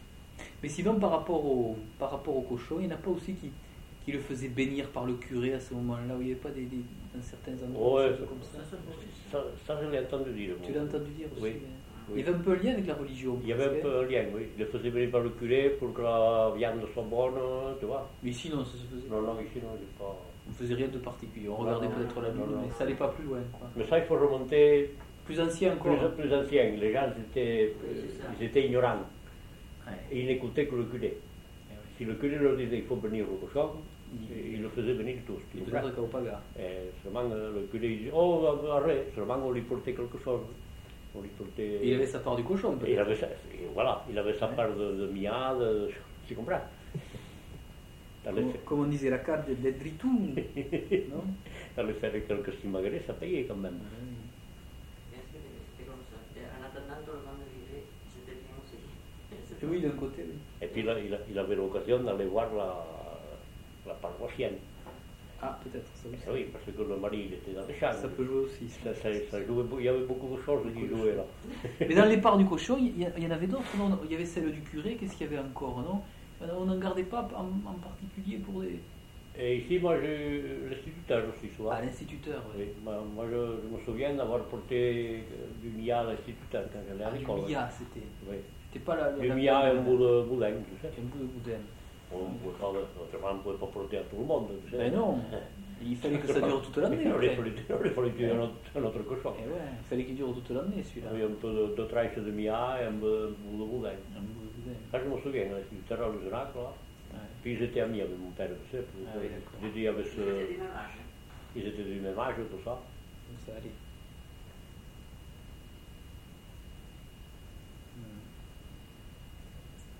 Lieu : Pouech de Luzenac (lieu-dit)
Genre : témoignage thématique